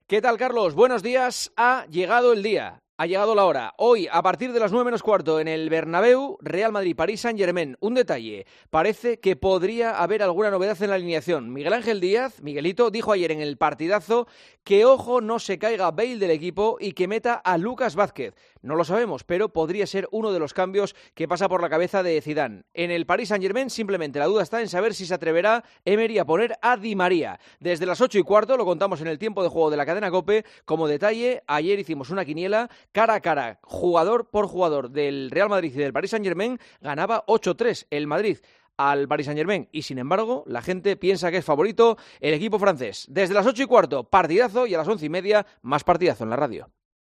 El comentario de Juanma Castaño 'EL PARTIDAZO' DE COPE